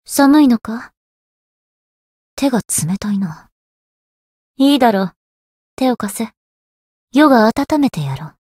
灵魂潮汐-萨缇娅-春节（摸头语音）.ogg